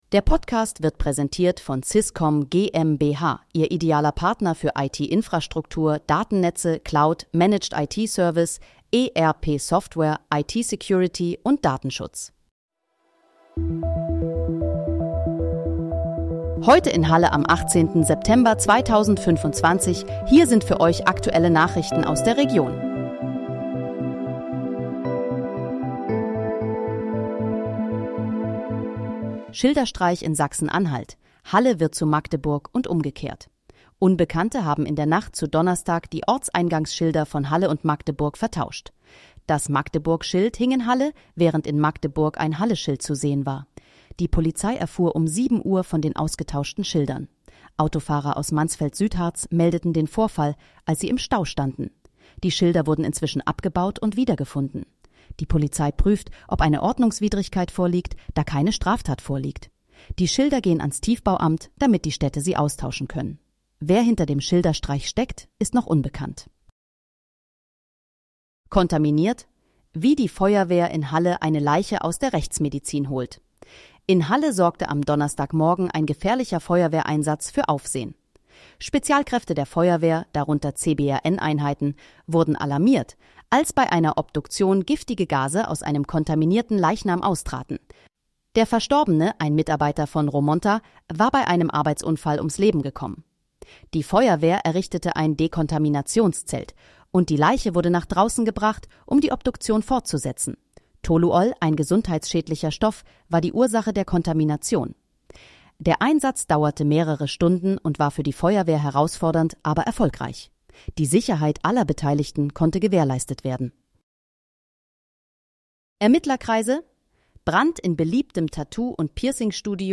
Heute in, Halle: Aktuelle Nachrichten vom 18.09.2025, erstellt mit KI-Unterstützung
Nachrichten